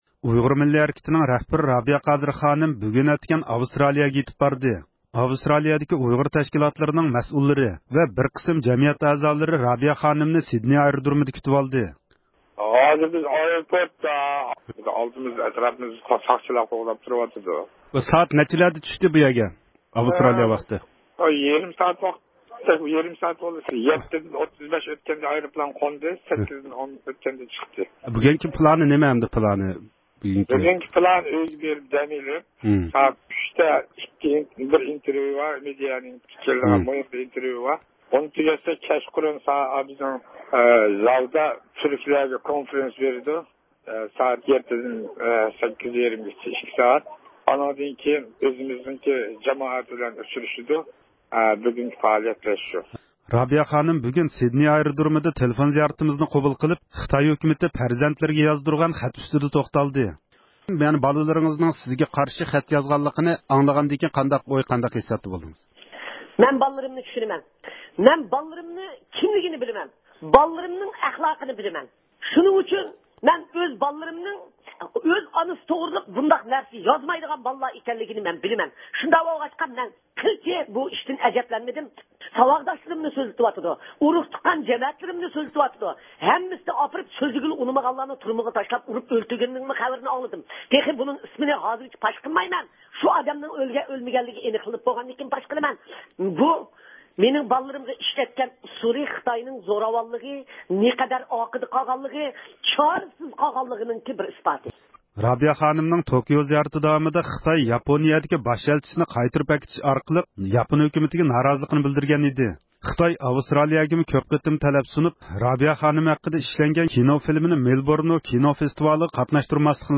رابىيە خانىم بۈگۈن سىدنېي ئايرودرومىدا تېلېفون زىيارىتىمىزنى قوبۇل قىلىپ پەرزەنتلىرىگە يازدۇرۇلغان خەت ئۈستىدە توختالدى.